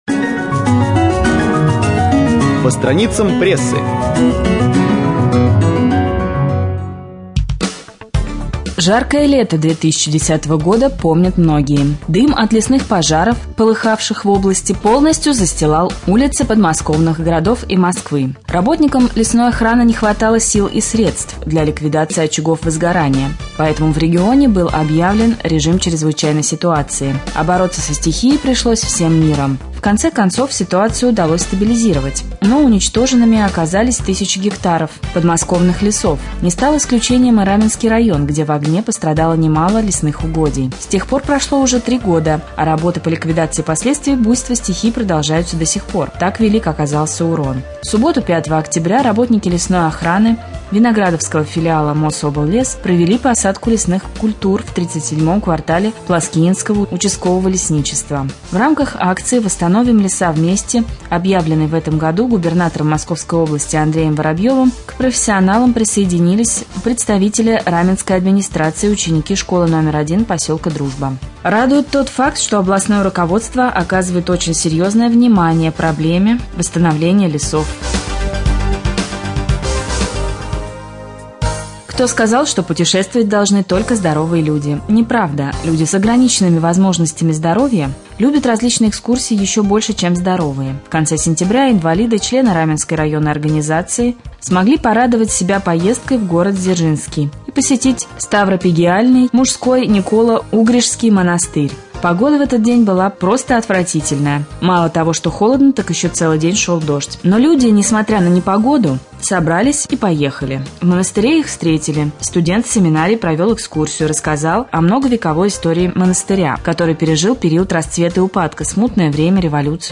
09.10.2013г в эфире раменского радио - РамМедиа - Раменский муниципальный округ - Раменское